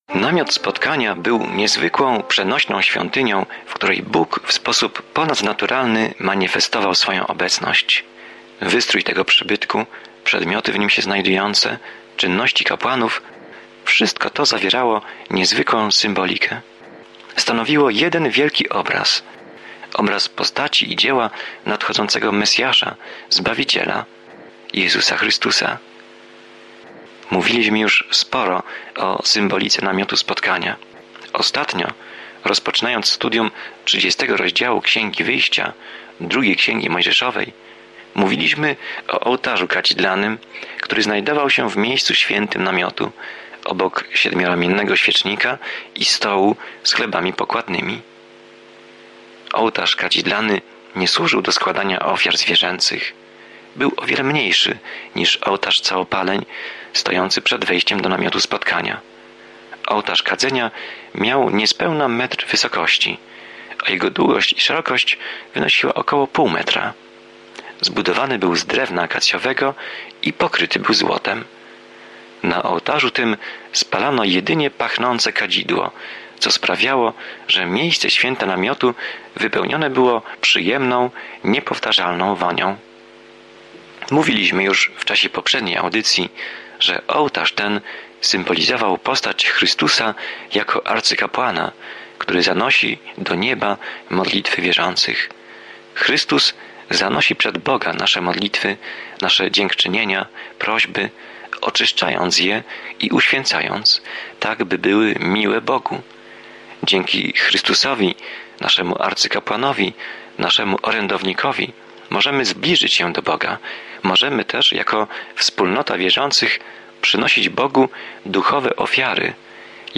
Pismo Święte Wyjścia 30:11-38 Wyjścia 31 Dzień 20 Rozpocznij ten plan Dzień 22 O tym planie Exodus śledzi ucieczkę Izraela z niewoli w Egipcie i opisuje wszystko, co wydarzyło się po drodze. Codzienna podróż przez Exodus, słuchanie studium audio i czytanie wybranych wersetów słowa Bożego.